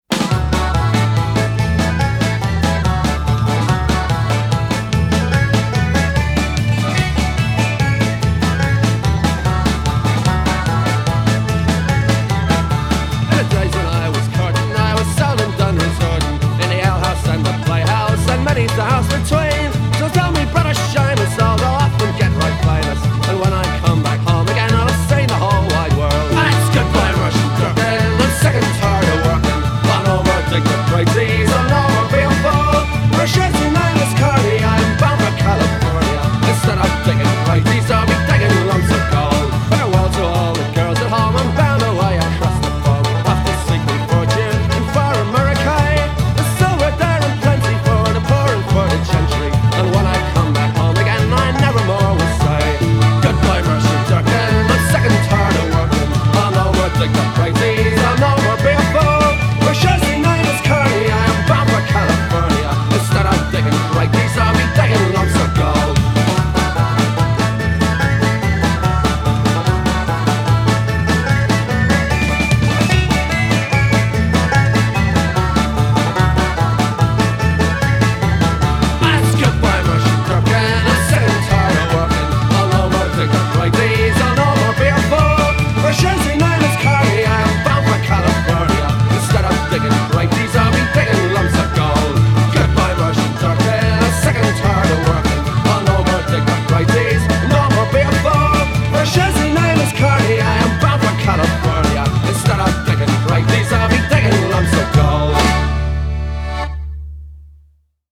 BBC Live 1984-89